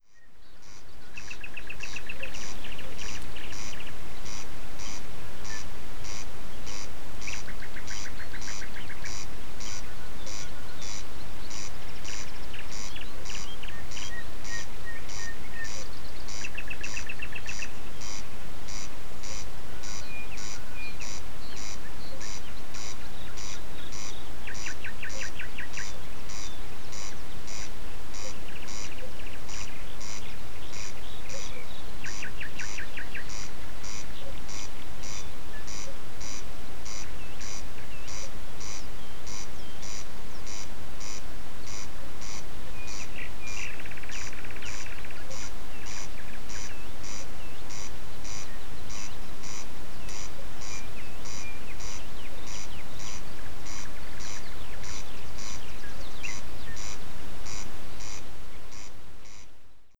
Audioaufnahme vom 28.05.2016 00:30, Schorfheide-Chorin. Zu hören sind der Wachtelkönig und die Nachtigall.